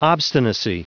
Prononciation du mot obstinacy en anglais (fichier audio)